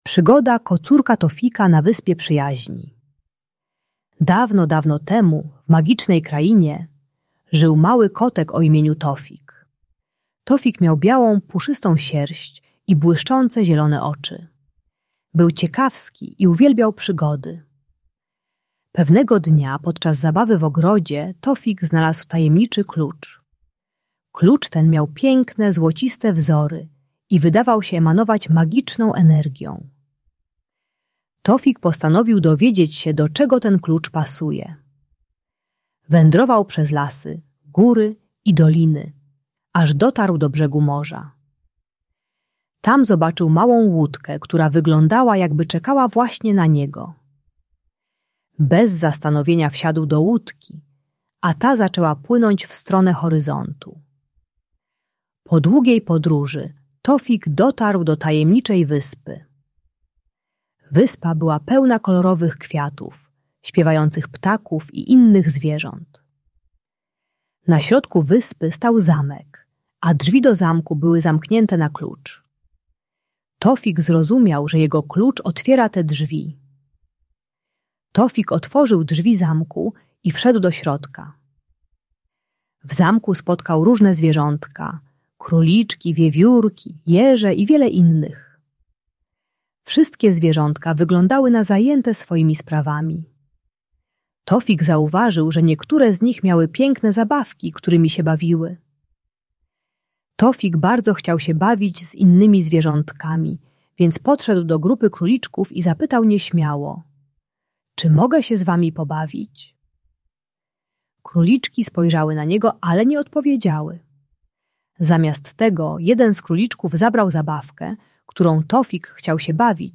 Uczy techniki wyrażania swoich emocji i potrzeb słowami - mówienia "czuję się smutny, bo chciałbym się z wami pobawić". Audiobajka o nieśmiałości i lęku przed odrzuceniem.